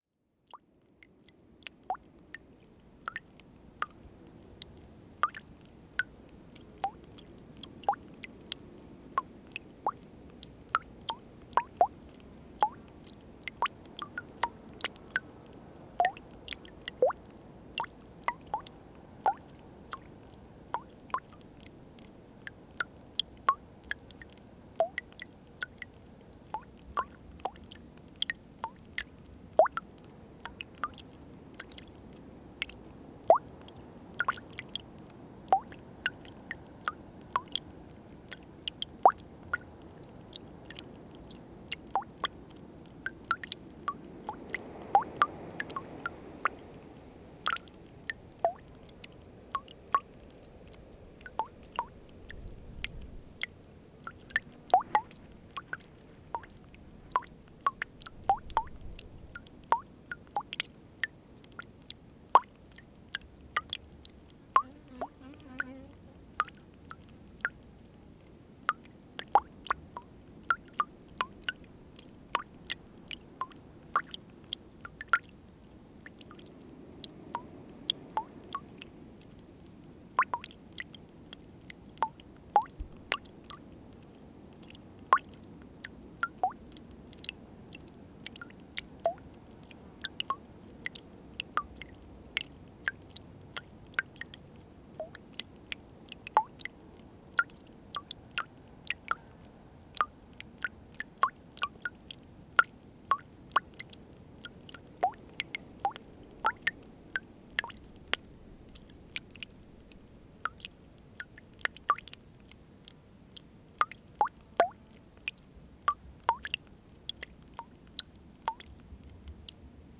NODAR.00557 – Calde: Escoamento de água junto ao Lugar da Fonte Santa
Paisagem sonora de escoamento de água junto ao Lugar da Fonte Santa em Calde, Calde a 14 Março 2016.
A água da Fonte Santa é fria e polissilábica.